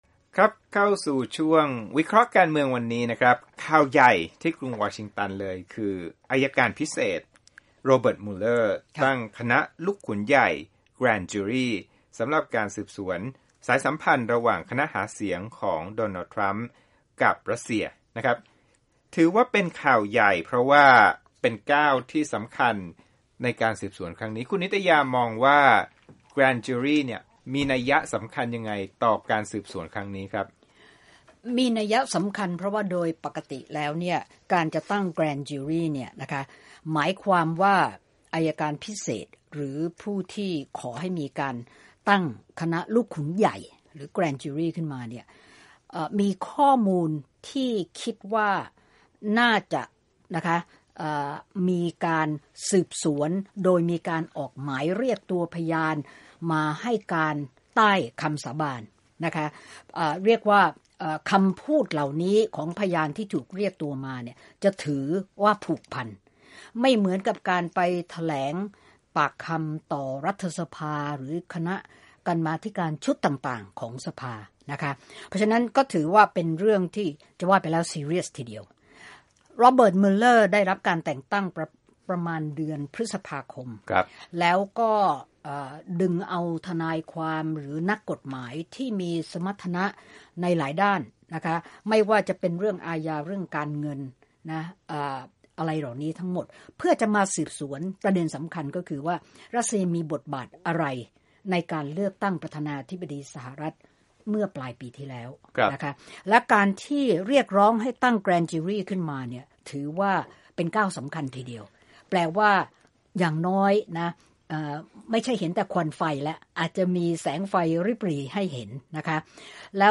ในรายการข่าวสดสายตรง